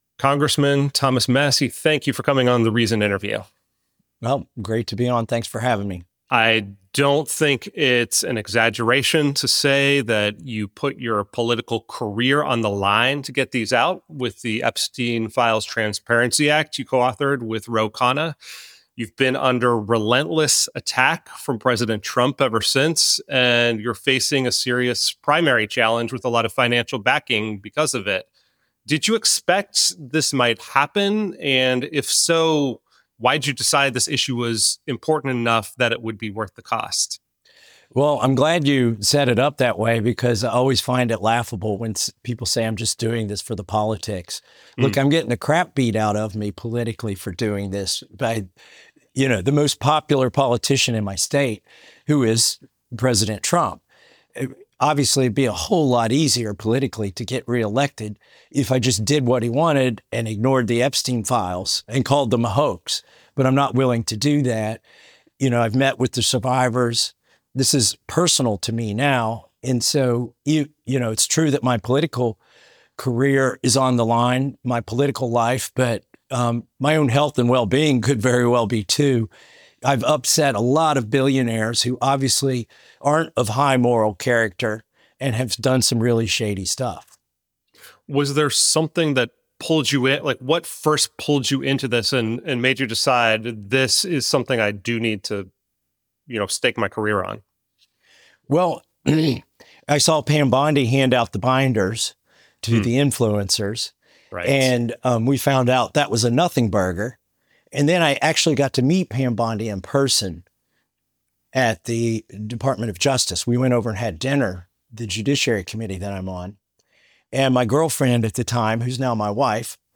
The Kentucky congressman tells Reason that Republicans and Democrats engaged in a “cover-up” of epic proportions that will haunt U.S. politics for years.